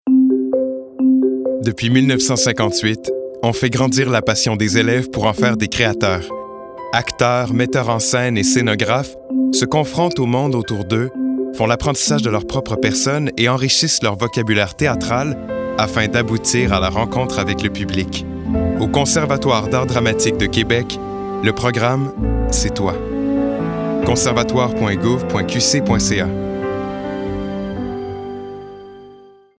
Publicité 2